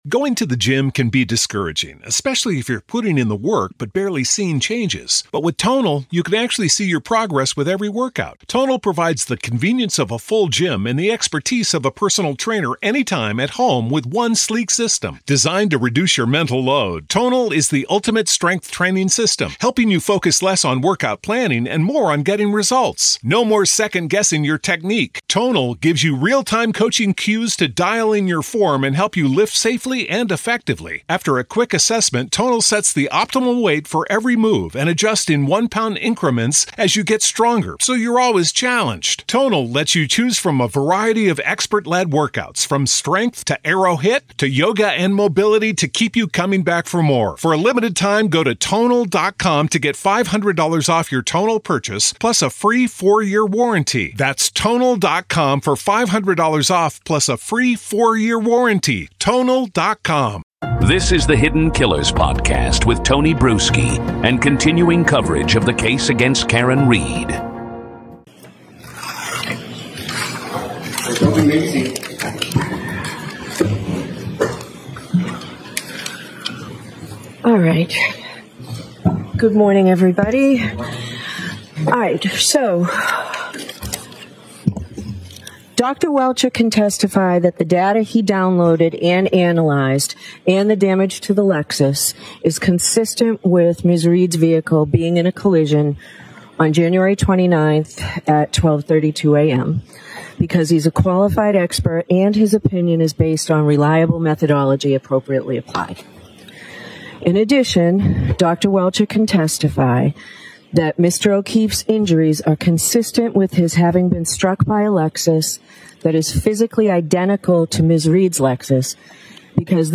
Attempting to poke holes in Aperture’s crash analysis and methodology, Read’s attorneys challenge assumptions, question visibility models, and raise issues of expert bias. This is the first round of a highly technical — and tactical — courtroom exchange.